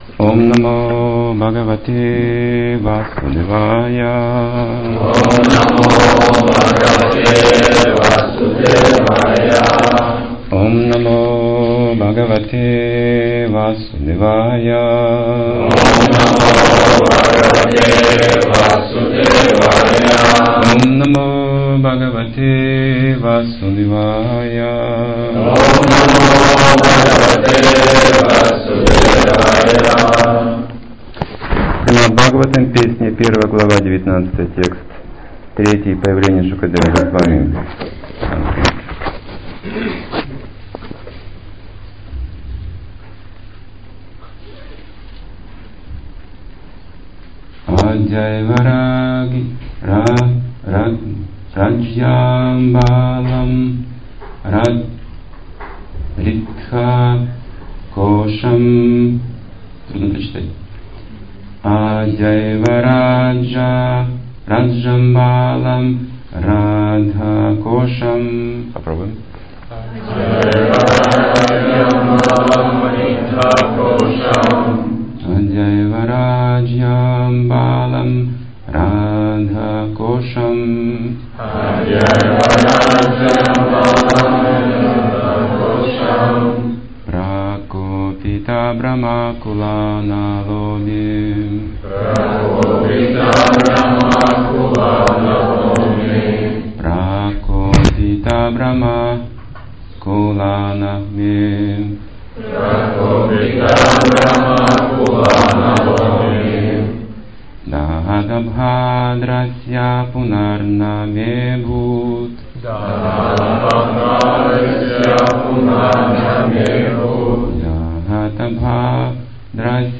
Темы, затронутые в лекции: Польза ошибок великих преданных Чтение мантры.